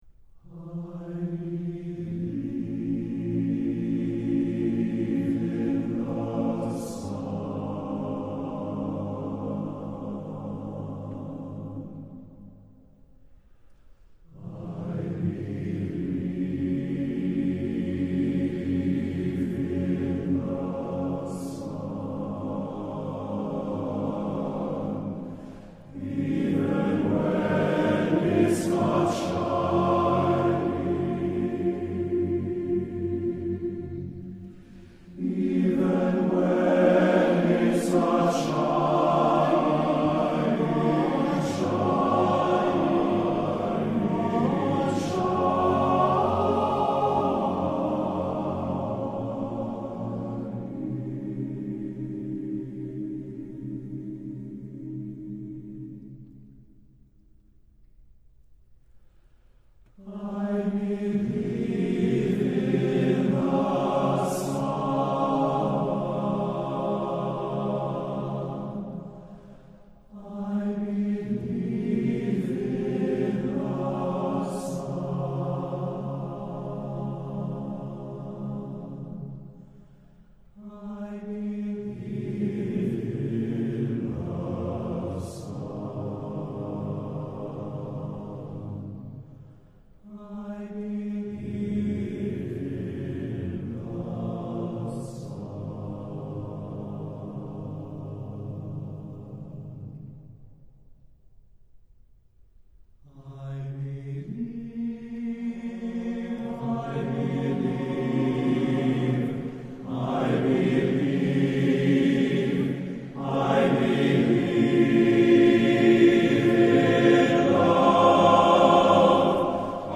Voicing: TTBB divisi a cappella